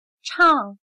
\chàng\Cantar